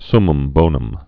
(sməm bōnəm)